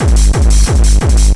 Index of /90_sSampleCDs/USB Soundscan vol.01 - Hard & Loud Techno [AKAI] 1CD/Partition A/01-180TBEAT